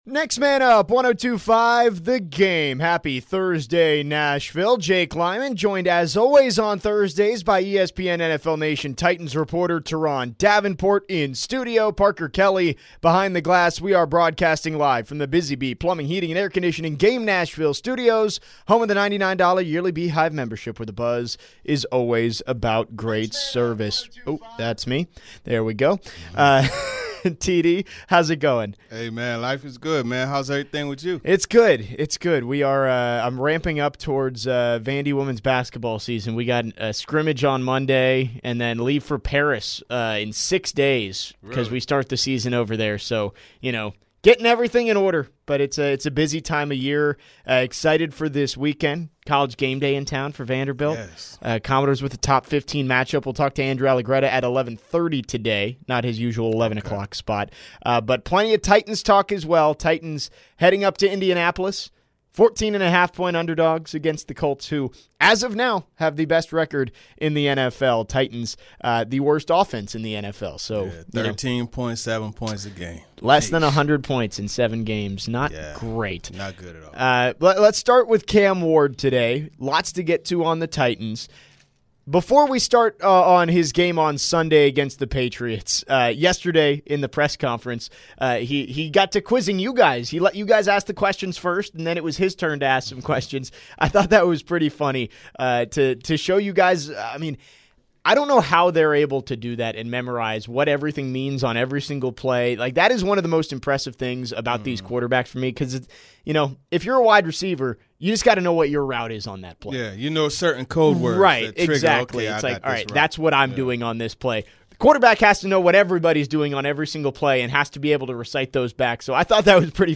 in studio today on Next Man Up. They break down Cam Ward's fumbling problem.